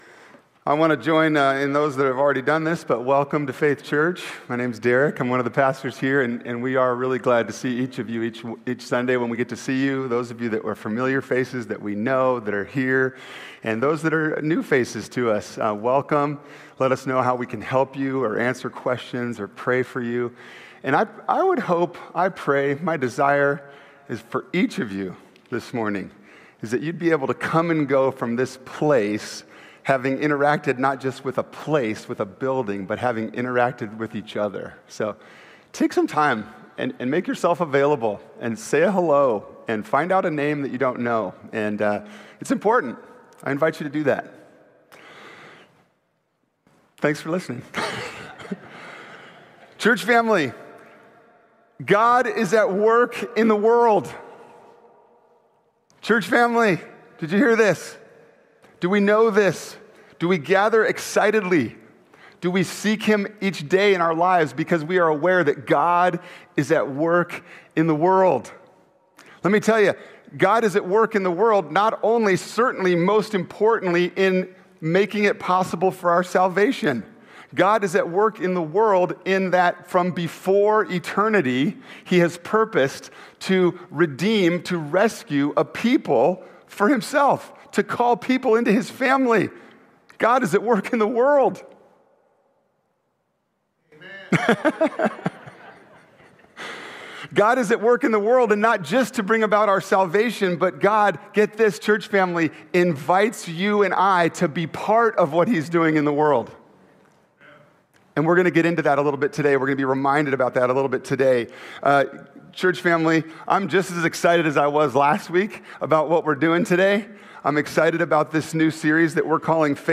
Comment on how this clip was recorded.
Il giorno è finalmente arrivato, abbiamo visto War of the Rohirrim. Ne abbiamo parlato subito dopo la visione, analizzando i motivi dello scarso successo di questa opera derivata.